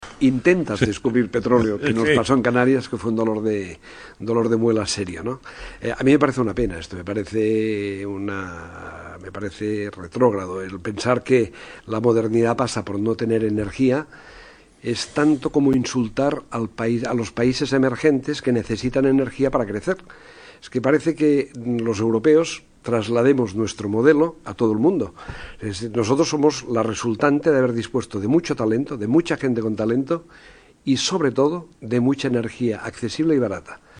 Antonio Brufau reconoce en Cadena Cope que el proceso vivido en las islas hace dos años fue "un dolor de muelas serio" para la compañía
"A mí esto me parece retrógrado, pensar que la modernidad pasa por no tener energía es tanto como insultar a los países emergentes que necesitan energía para crecer. El mundo necesita energía, lo que pasó en Canarias me pareció una pena, tercermundista", dijo en declaraciones a la Cadena Cope.
brufau.mp3